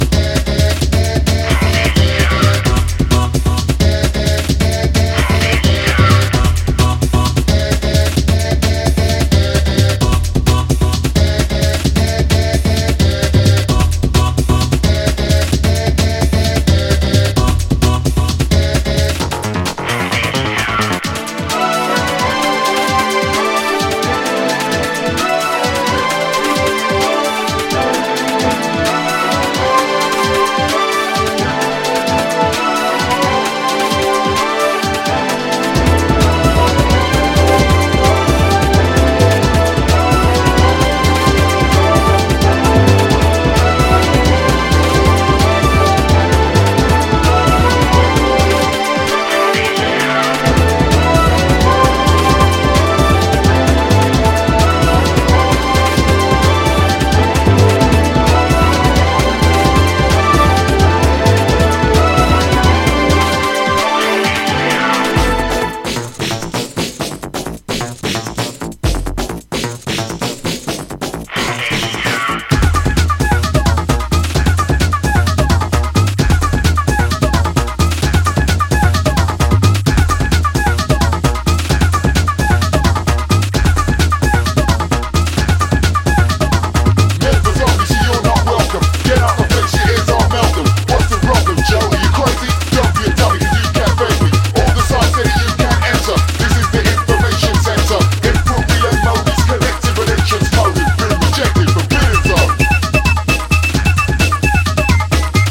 Old Skool Hardcore / Old Skool Techno / Old Skool Breakbeat